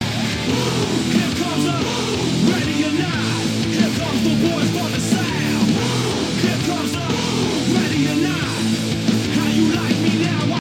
Play, download and share BOOM!_POD original sound button!!!!
boom_llbESAd.mp3